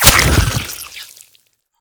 biter-death-3.ogg